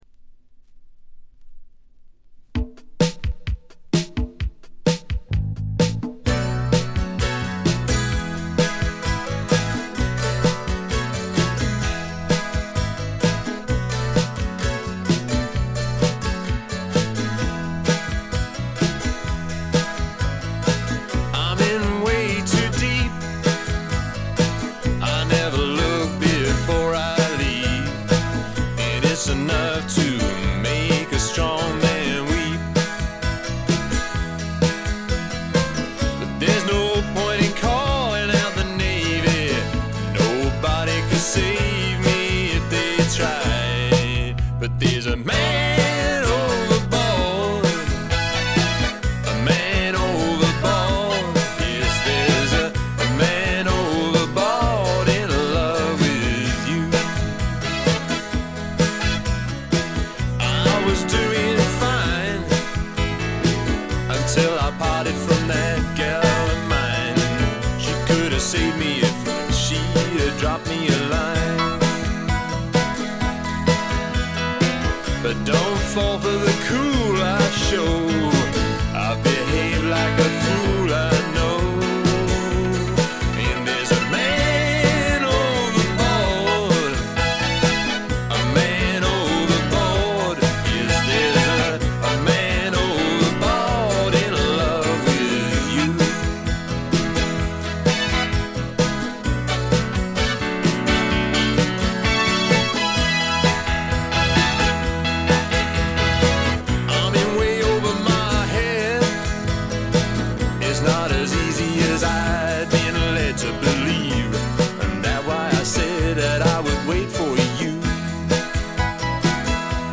ごくわずかなノイズ感のみ。
試聴曲は現品からの取り込み音源です。
Vocals Guitars
Keyboards
Balalaika
Bass Vocals
Recorded at Turboways Studio, Lonbdon 1985/6.